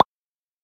openWindow.mp3